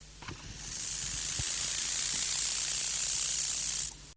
Акустические сигналы: одиночный самец с одной задней ногой, Россия, Тува, Овюрский район, запись
Температура записи 22-24° С.